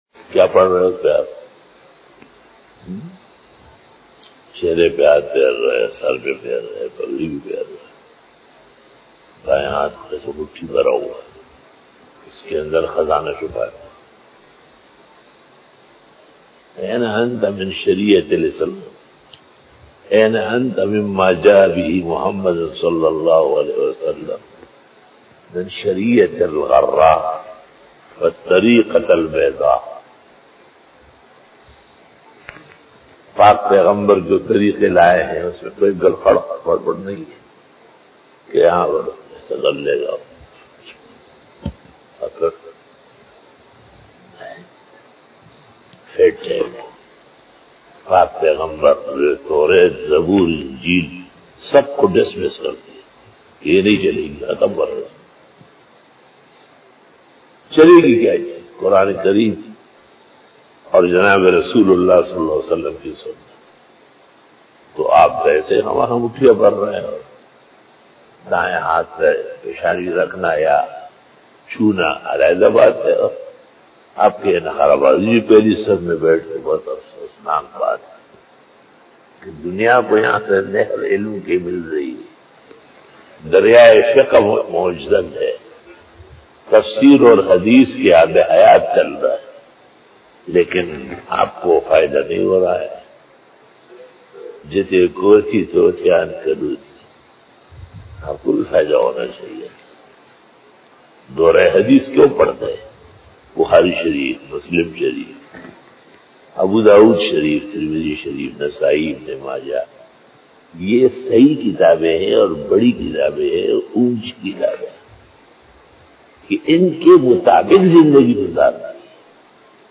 After Namaz Bayan
بیان بعد نماز فجر